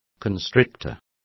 Complete with pronunciation of the translation of constrictors.